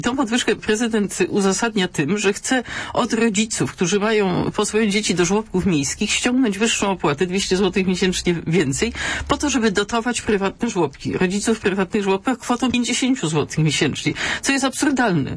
Podwyżki to zły pomysł - mówiła w poranku "Radia Merkury" wiceprzewodnicząca Rady Miasta i radna SLD - Katarzyna Kretkowska.